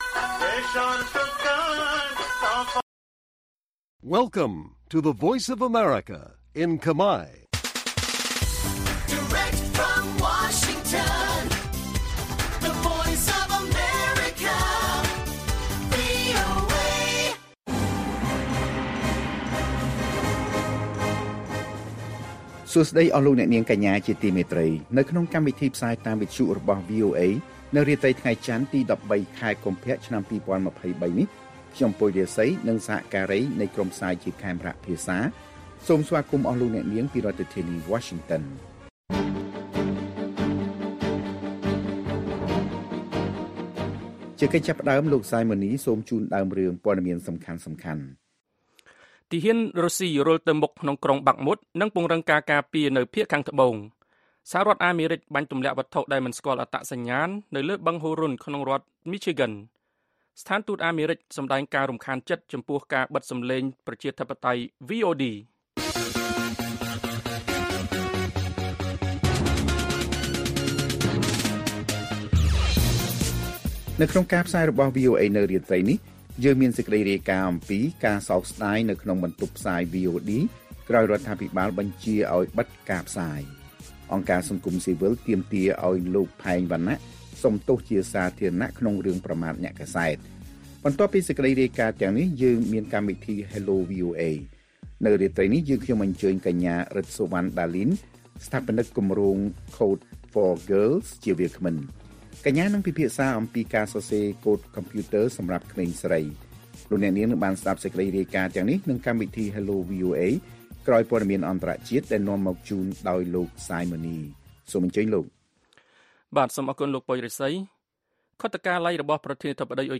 ព័ត៌មានពេលរាត្រី ១៣ កុម្ភៈ៖ ការសោកស្តាយនៅក្នុងបន្ទប់ផ្សាយវីអូឌី ក្រោយរដ្ឋាភិបាលបញ្ជាឱ្យបិទការផ្សាយ